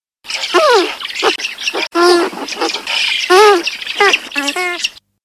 Spatule blanche
Platalea leucorodia
spatule.mp3